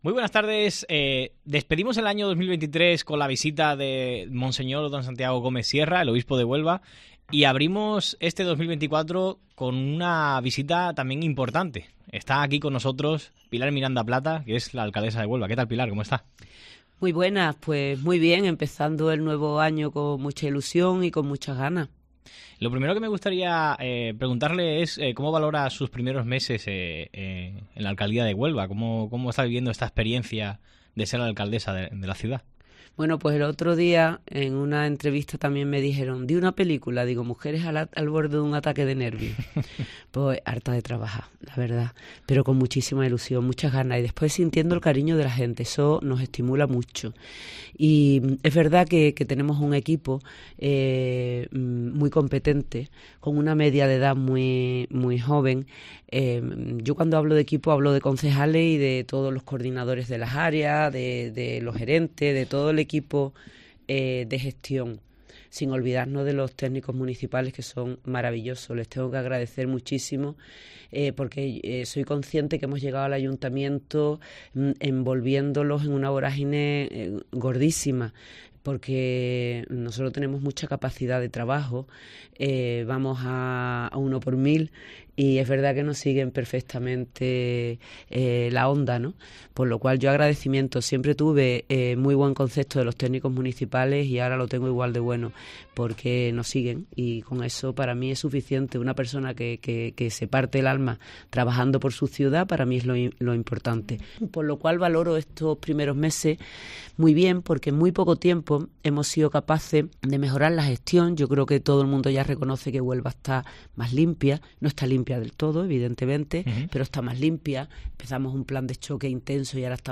Entrevista completa a Pilar Miranda, alcaldesa de Huelva